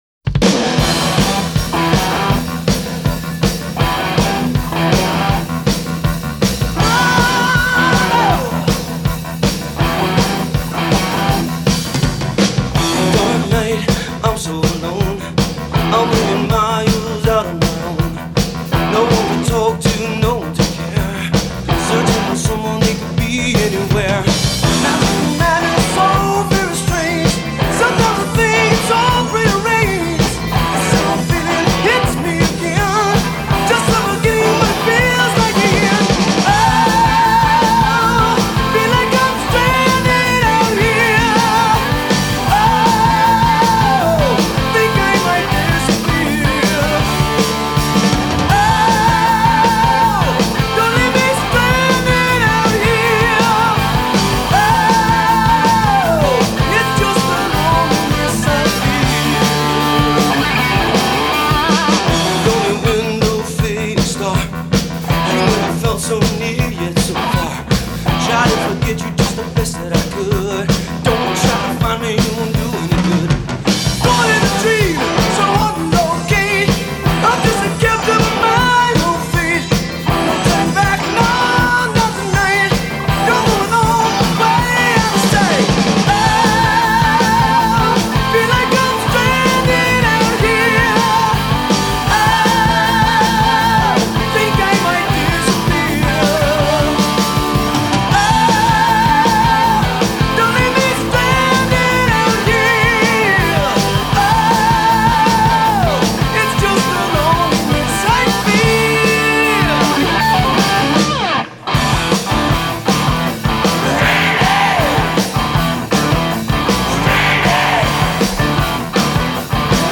Жанр: Хард-рок, AOR